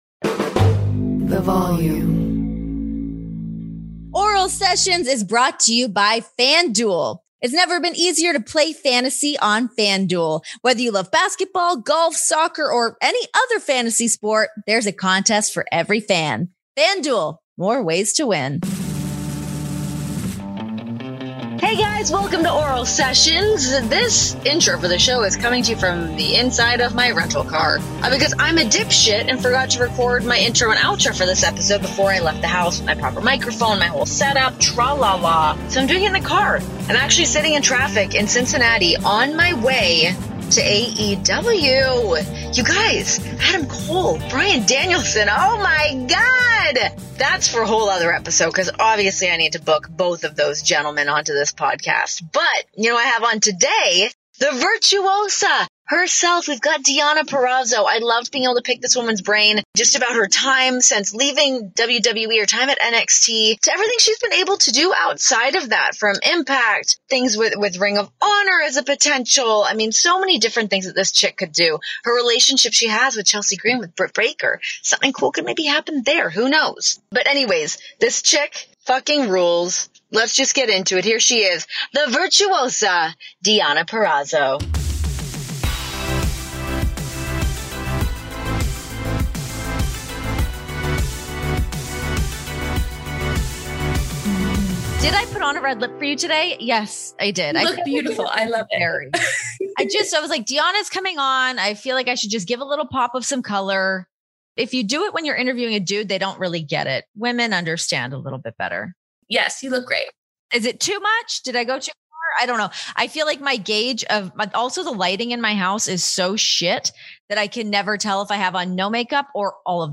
Deonna Purrazzo is the Virtuosa in the ring and on the latest episode of Oral Sessions, as the IMPACT Knockouts and AAA Reina de Reinas Champion joins the pod to discuss a wild career. From her creative dissatisfaction within NXT to finding her passion in IMPACT post-release to her current obsession with the Civil War, check out one of our most interesting interviews yet.